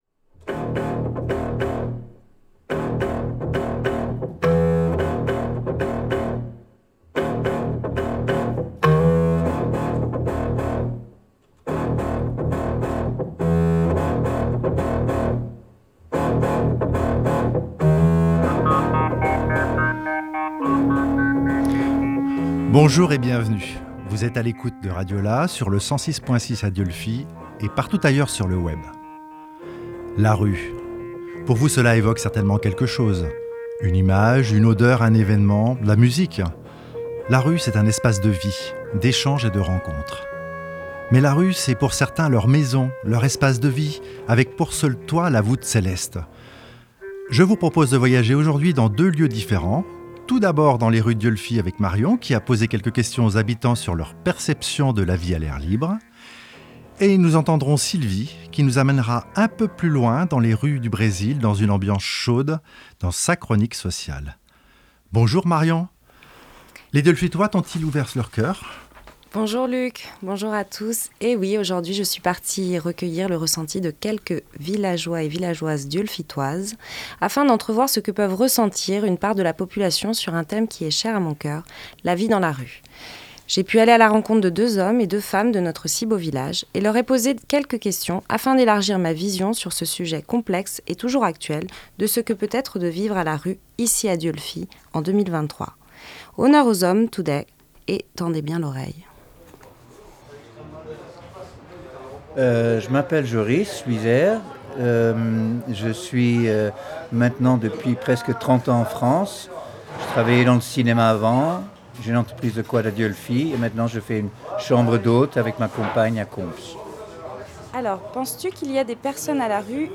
Pour une émission qui abordera les histoires de rue avec conscience et humour.